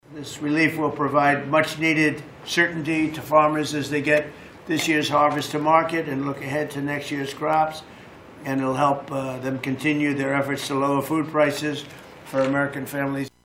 TRUMP MADE THE ANNOUNCEMENT MONDAY DURING AN EVENT AT THE WHITE HOUSE.